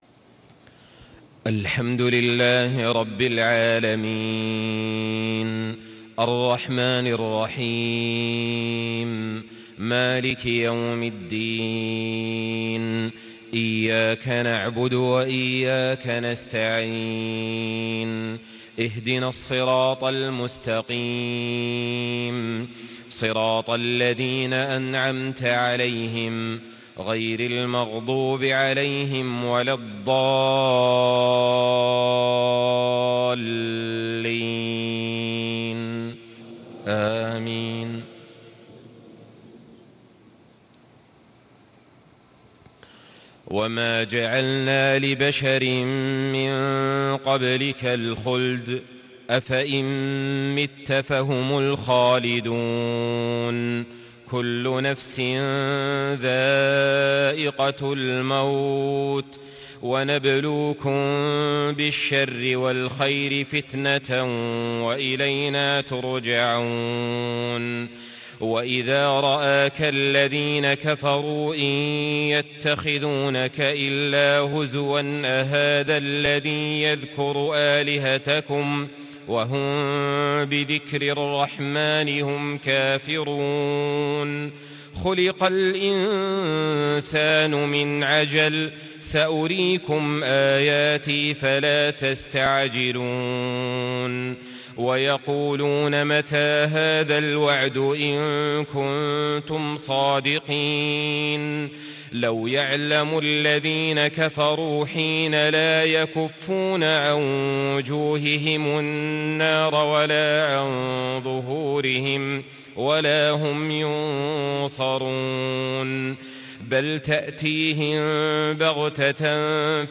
صلاة العشاء 22 شوال 1437هـ من سورة الأنبياء 34-50 > 1437 🕋 > الفروض - تلاوات الحرمين